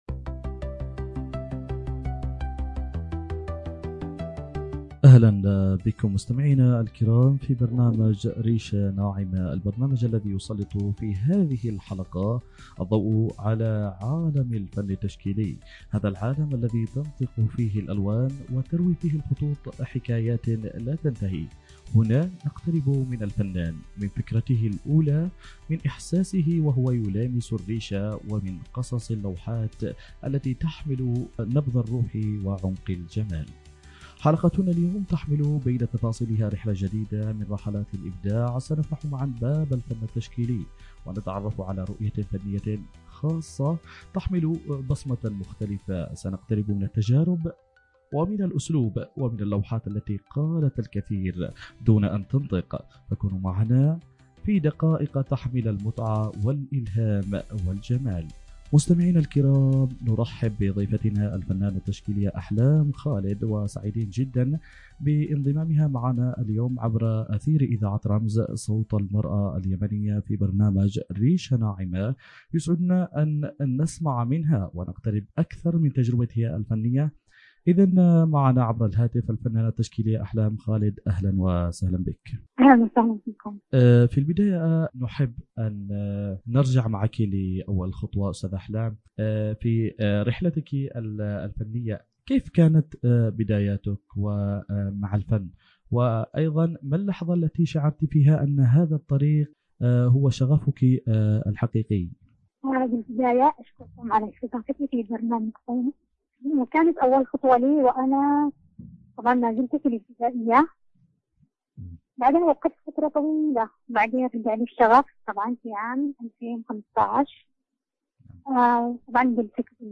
حوار
عبر أثير إذاعة رمز